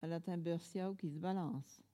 Collectif atelier de patois